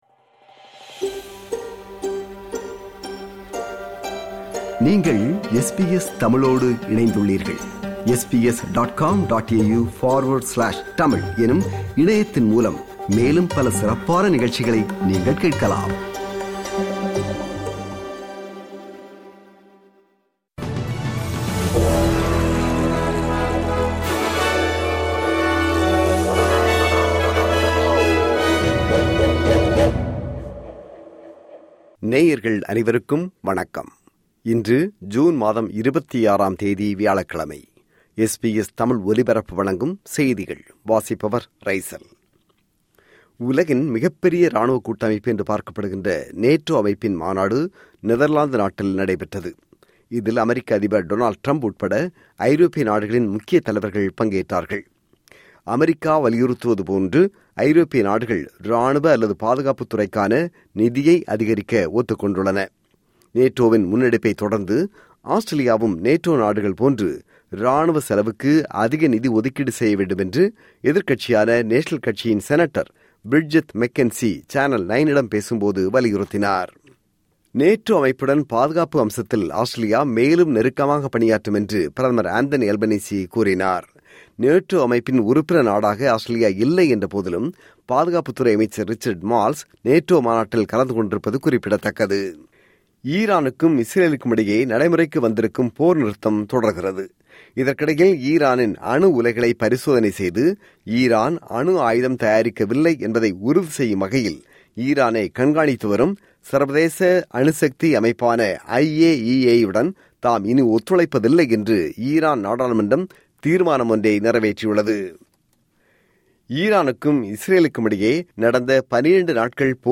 SBS தமிழ் ஒலிபரப்பின் இன்றைய (வியாழக்கிழமை 26/06/2025) செய்திகள்.